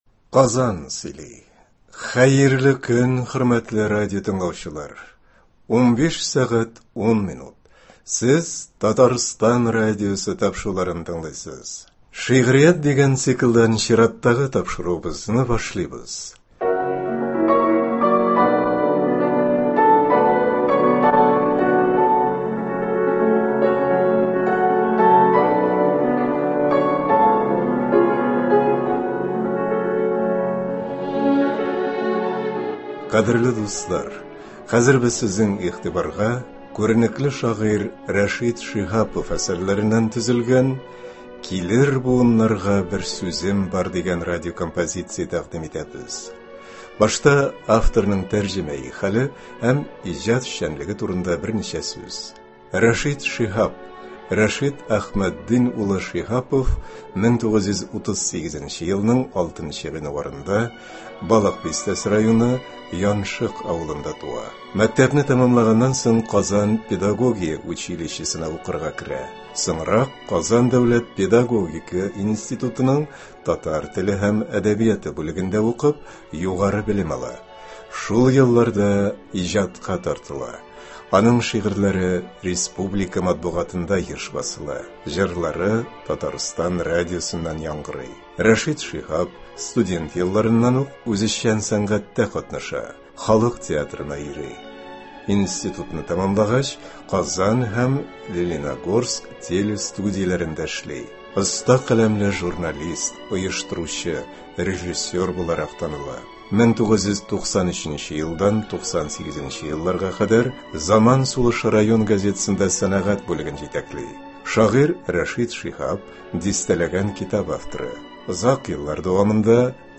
Радиокомпозиция.